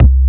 45 BASS 1 -L.wav